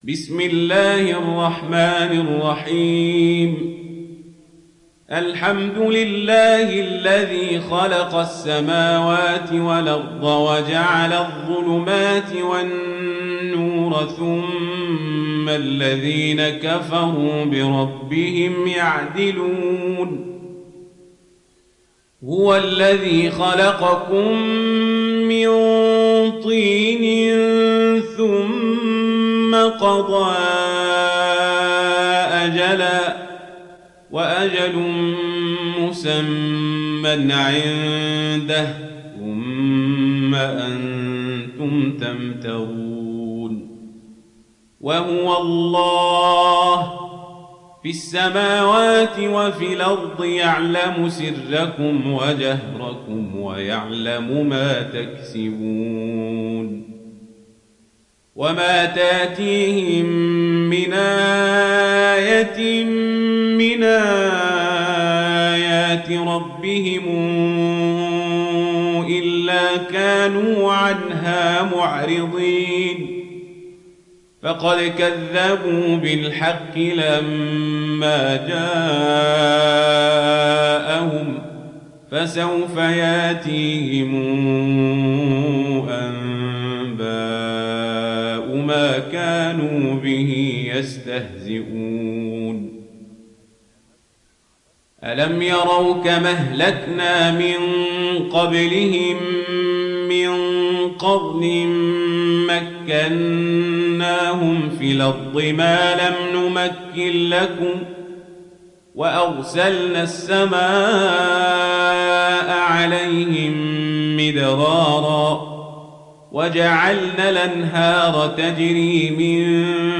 Sourate Al Anaam mp3 Télécharger Omar Al Kazabri (Riwayat Warch)